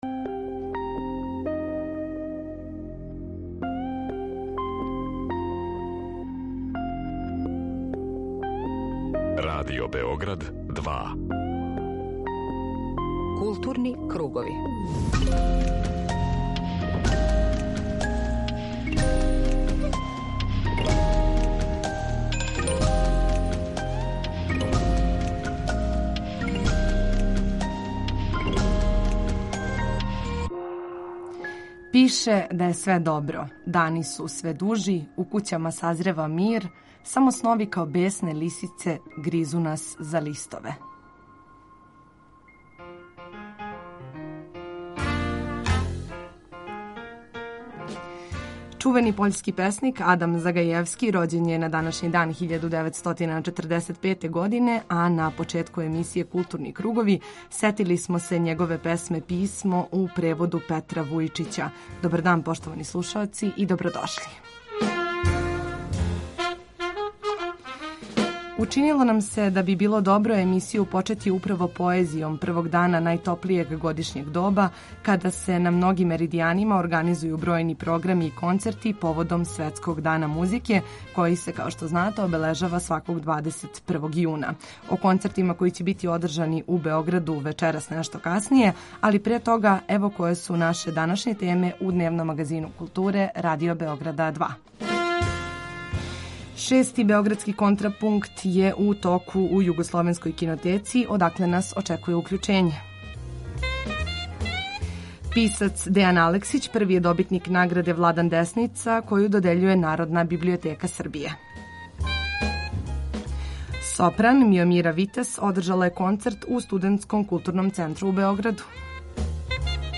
Група аутора Централна културно-уметничка емисија Радио Београда 2.